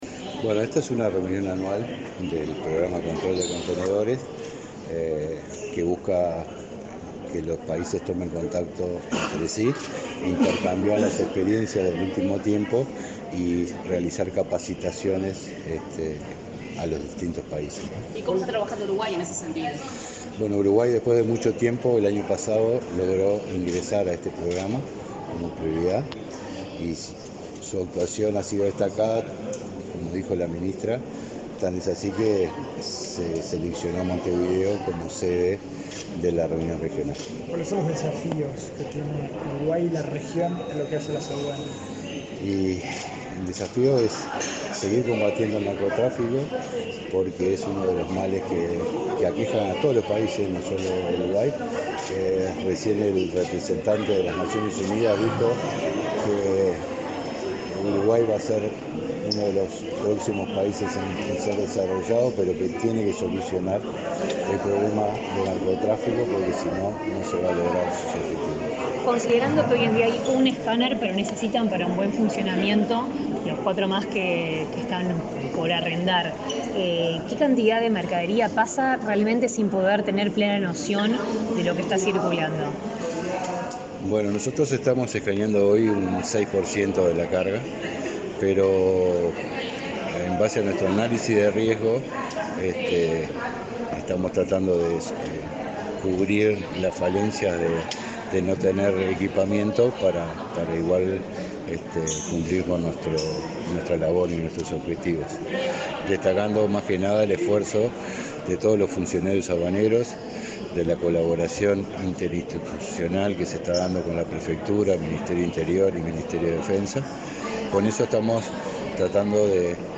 Declaraciones del director nacional de Aduanas
Luego dialogó con la prensa.